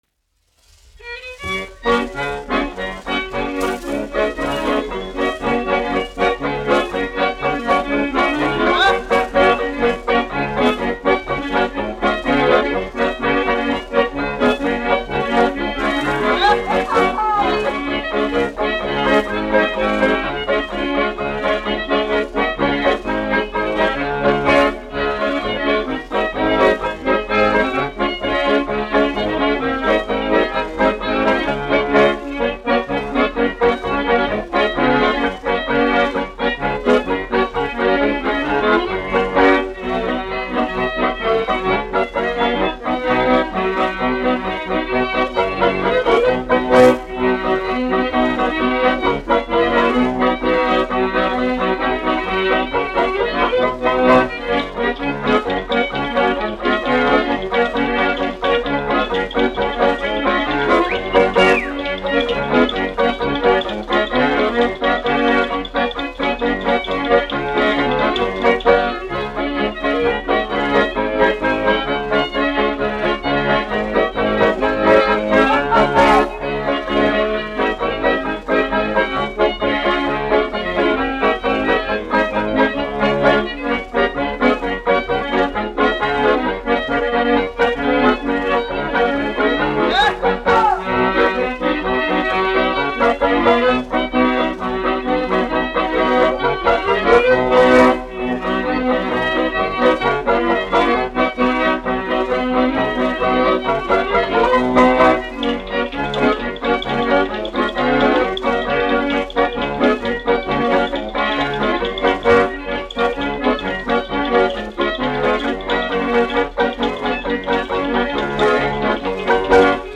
1 skpl. : analogs, 78 apgr/min, mono ; 25 cm
Polkas
Latvijas vēsturiskie šellaka skaņuplašu ieraksti (Kolekcija)